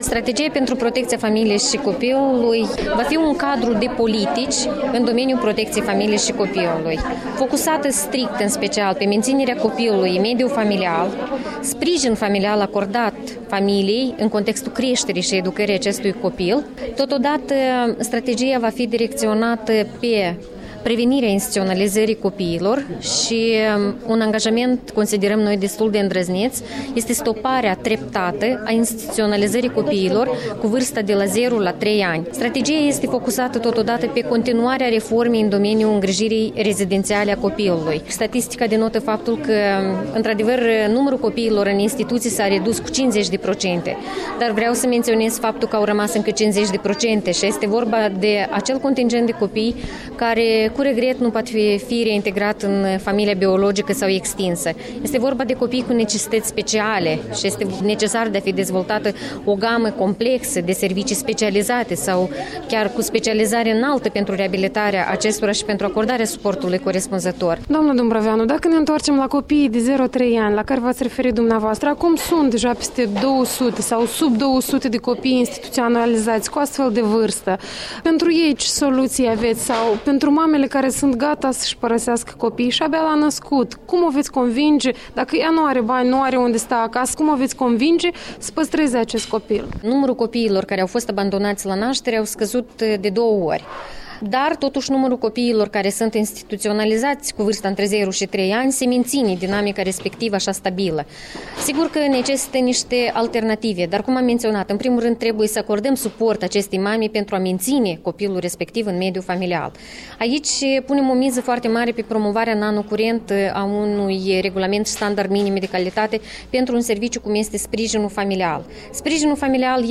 O strategie de protecție a familiei și copilului: de vorbă cu Viorica Dumbrăveanu